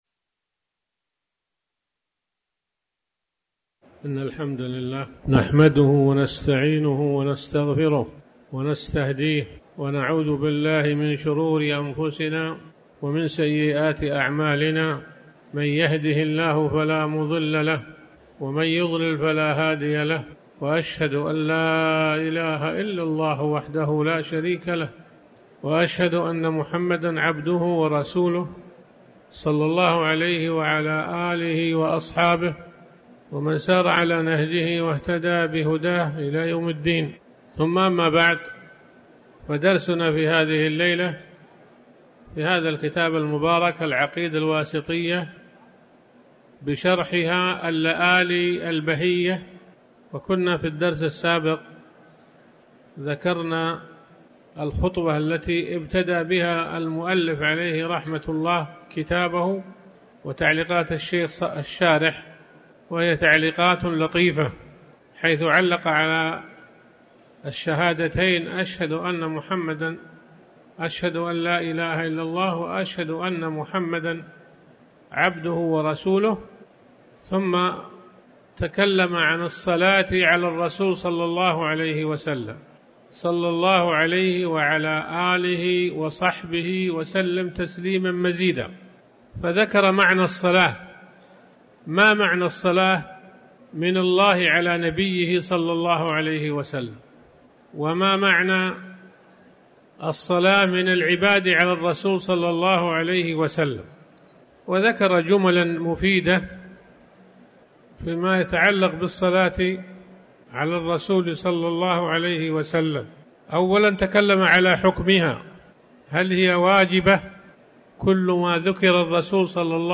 تاريخ النشر ١٨ شعبان ١٤٤٠ هـ المكان: المسجد الحرام الشيخ